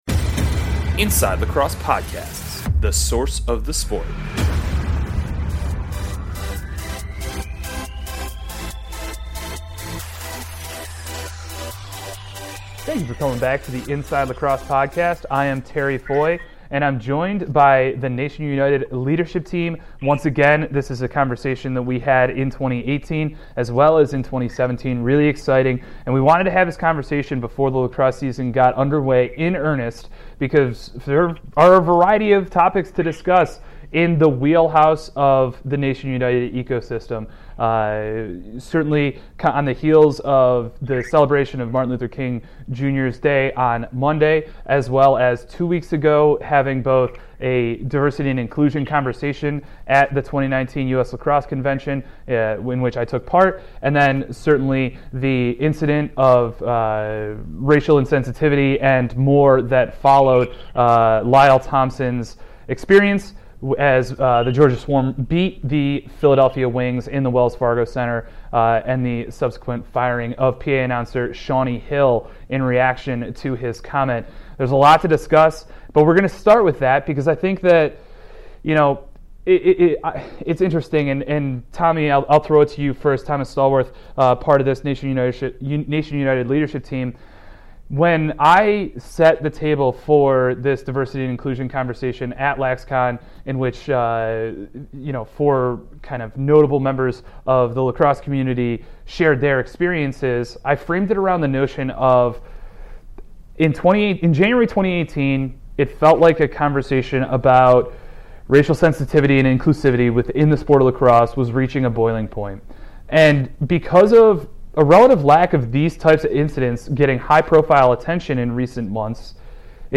1/26 Diversity Discussion with the Nation United Leadership